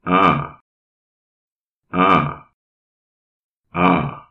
aah (long sound)